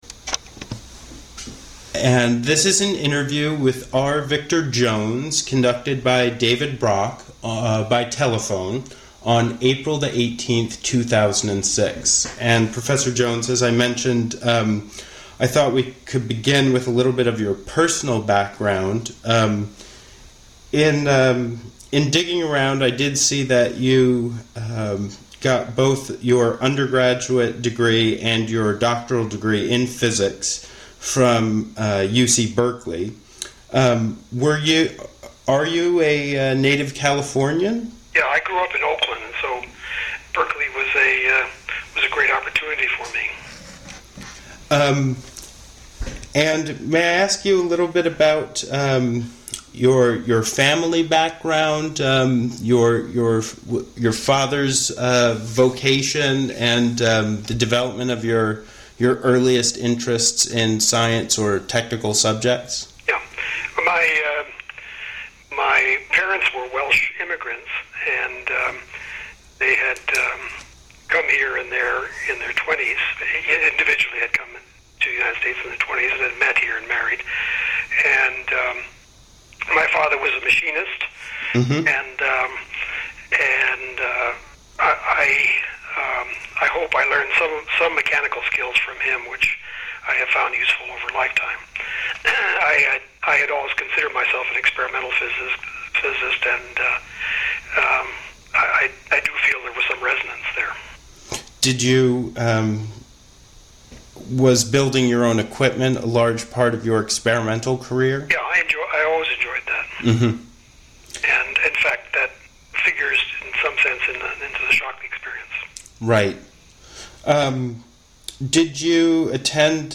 Place of interview Telephone
Genre Oral histories